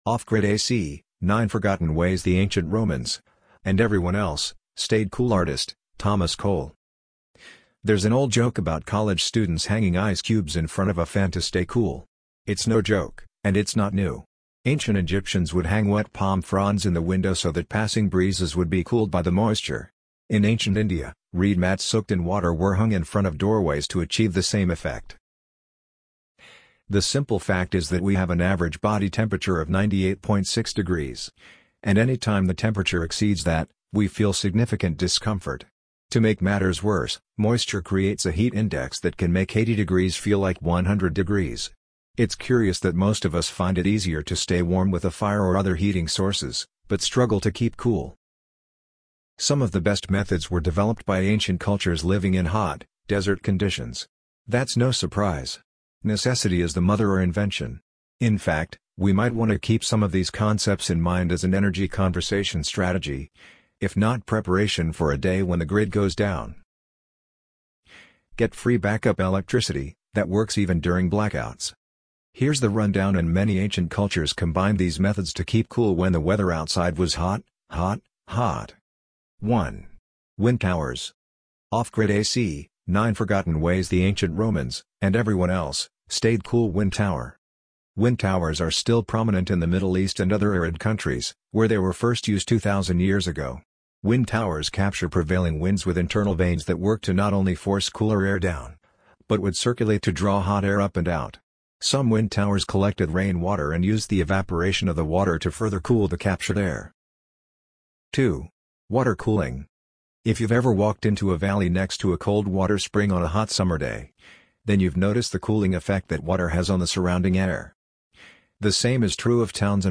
amazon_polly_68754.mp3